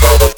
(Дроп начинается на 39 секунде) Наиболее чистый пример звука прилагается И хотелось бы увидеть пример накрутки в синте Serum